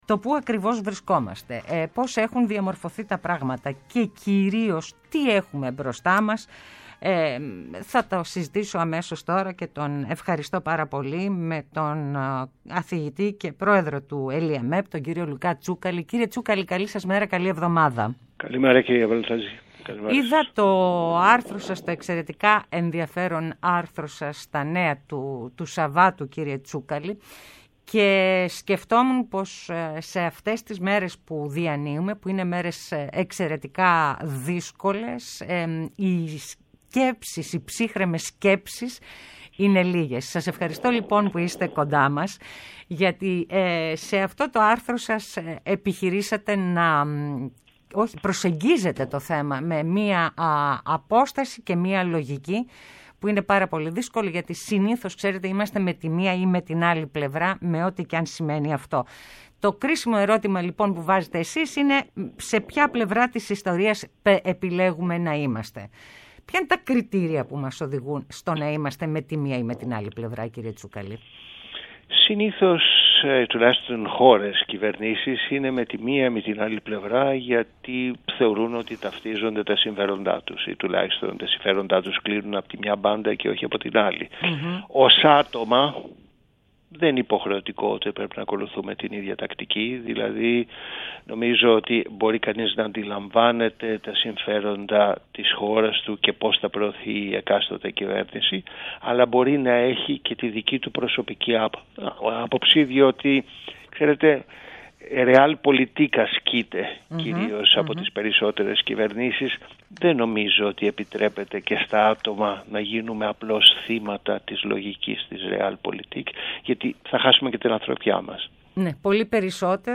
συζητά στην εκπομπή “Ναι μεν αλλά”